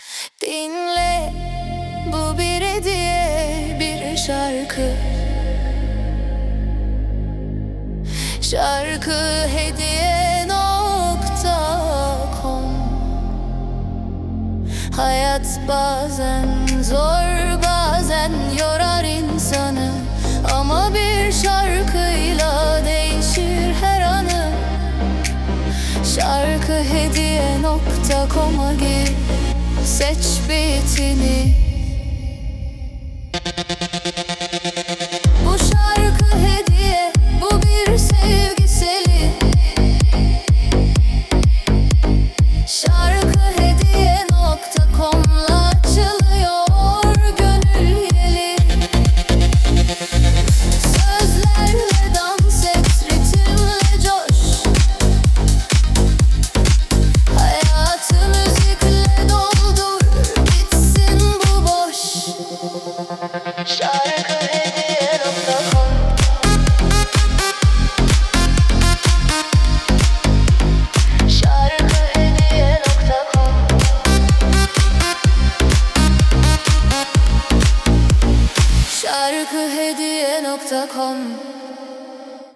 🎤 Vokalli 19.10.2025